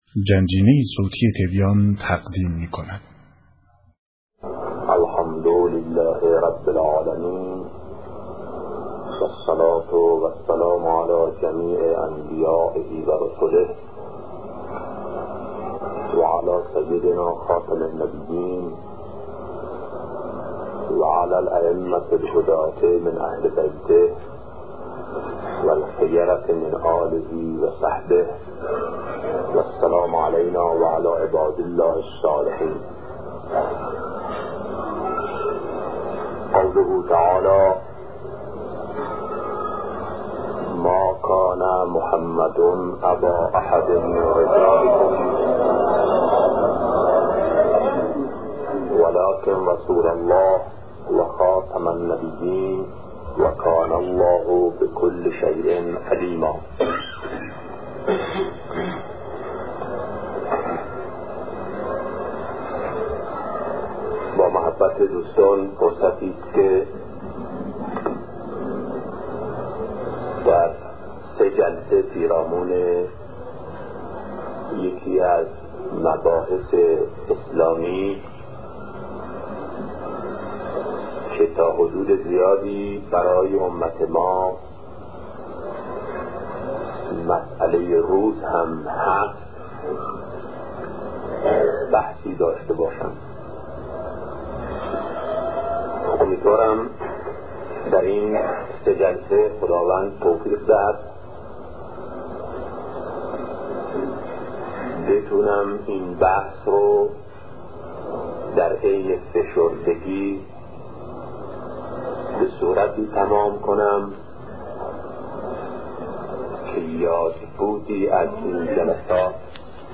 سخنرانی شهید بهشتی(ره) - با موضوع حقیقت خاتمیت - بخش‌اول